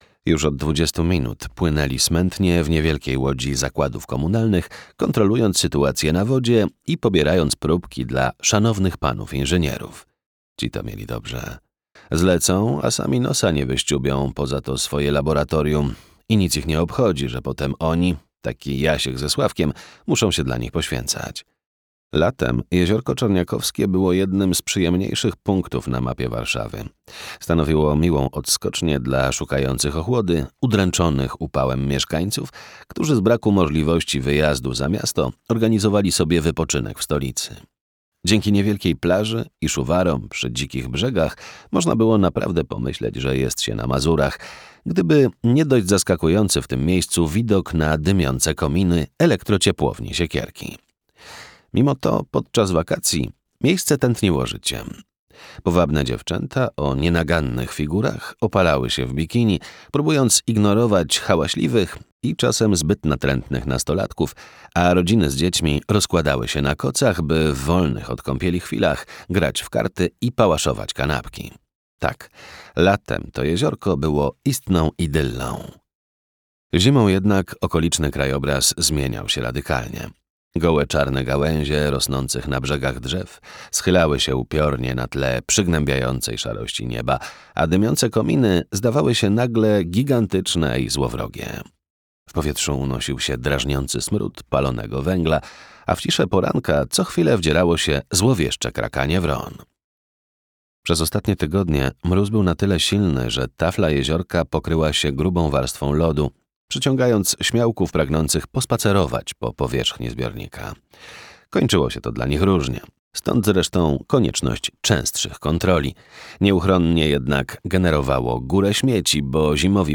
Audiobook Martwe kwiaty, Marta Reich.